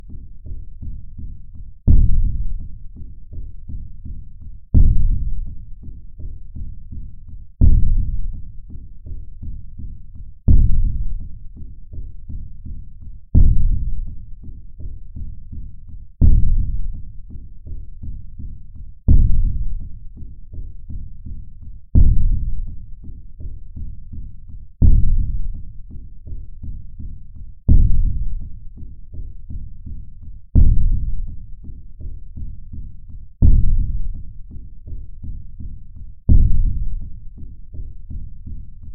Нагоняющие страх звуки для монтажа видео и просто испугаться слушать онлайн и скачать бесплатно.